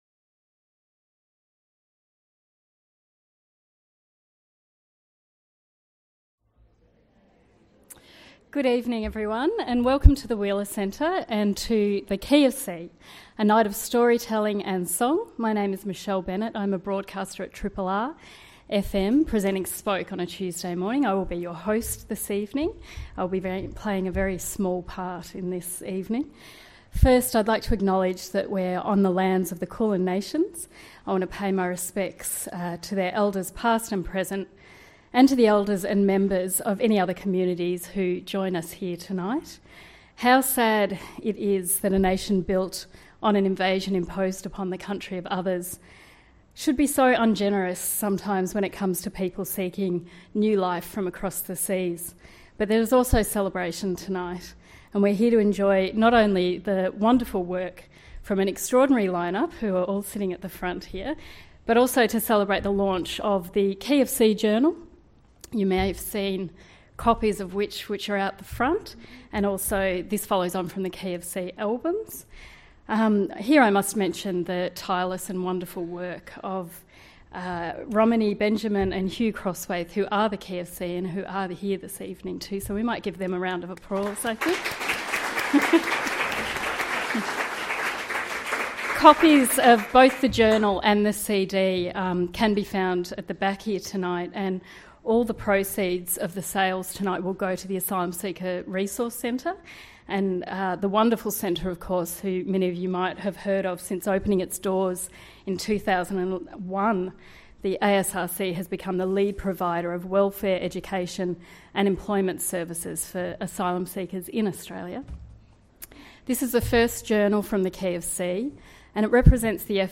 Join us for an emotional night of storytelling and song.